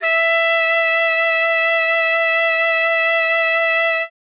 42c-sax10-e5.wav